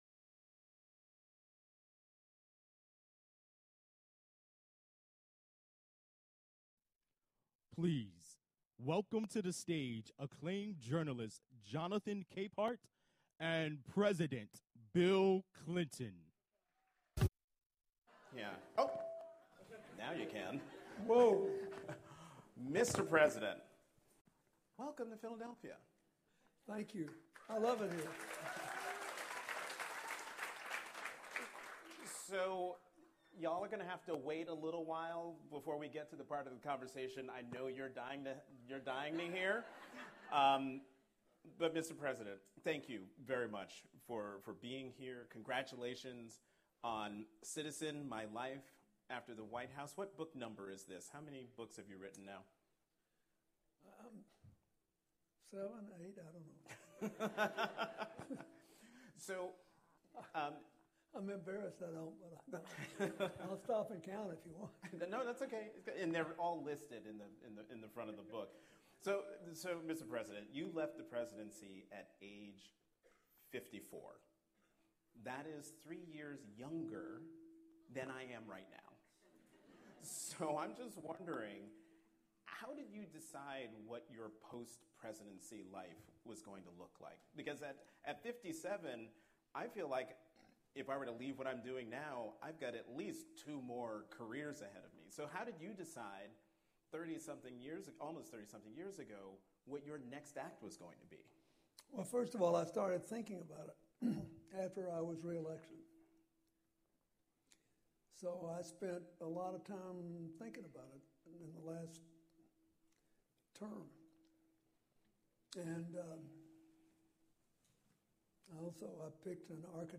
The Author Events Series presents Bill Clinton | Citizen: My Life After the White House